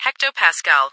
- New ATIS Sound files created with Google TTS en-US-Studio-O